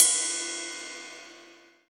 Ride_03.wav